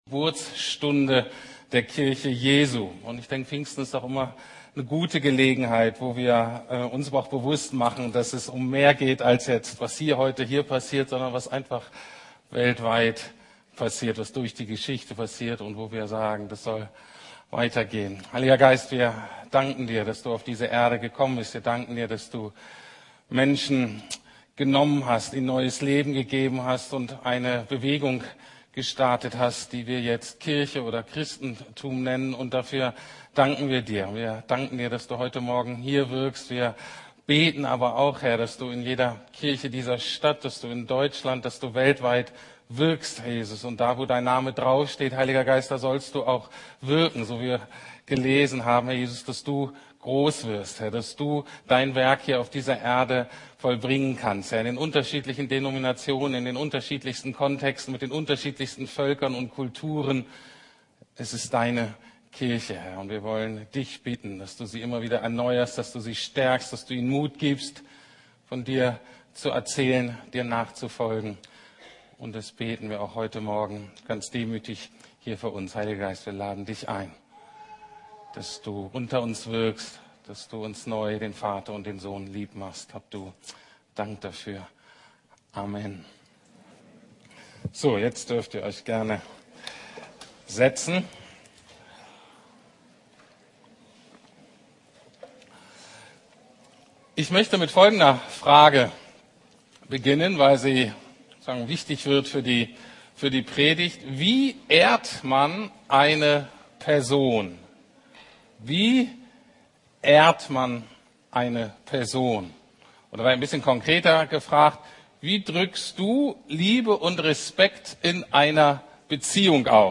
Paulus - Gerechtfertigt aus Glauben ~ Predigten der LUKAS GEMEINDE Podcast